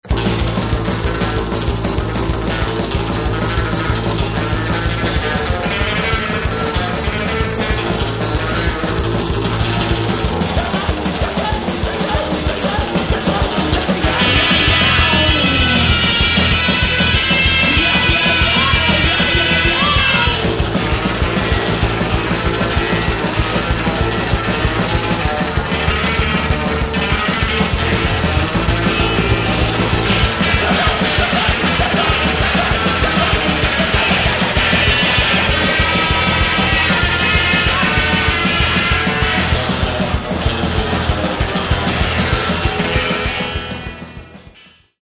1999 exciting fast voc.